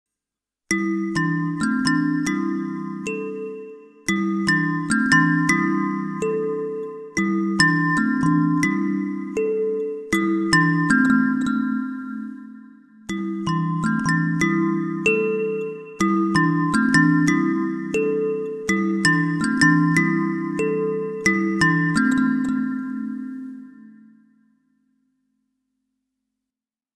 Have you considered an electric kalimba to record/sample?
I have one, I’m not very good at it but it sounds lovely!